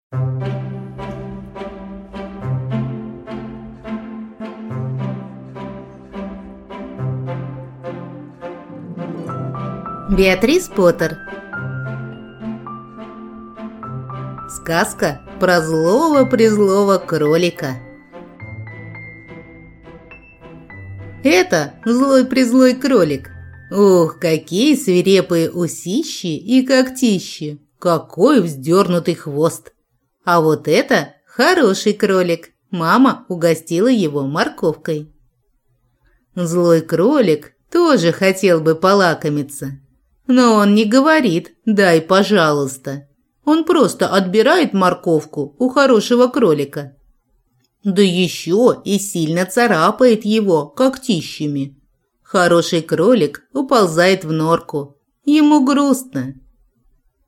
Аудиокнига Сказка про злого-презлого кролика | Библиотека аудиокниг